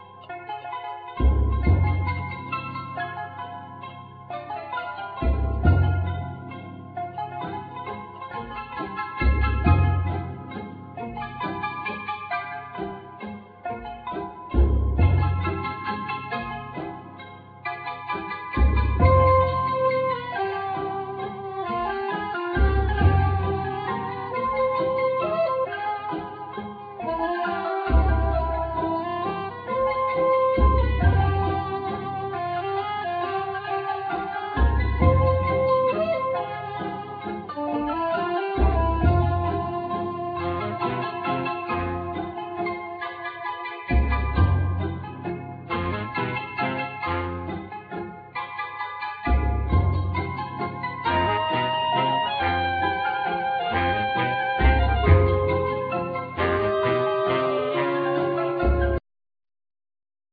Trombone
Basson
Trampet,Trampet Piccolo
Zarb,Viele,Timbales
Harp
Soprano
Baryton
Chorus